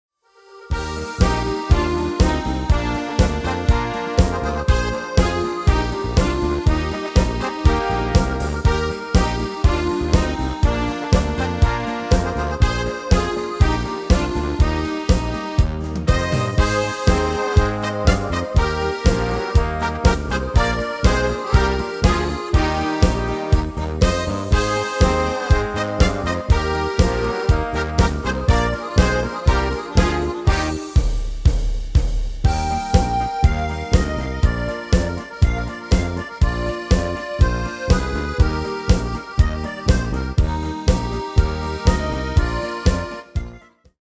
Demo/Koop midifile
Genre: Carnaval / Party / Apres Ski
Toonsoort: Eb/F
- Vocal harmony tracks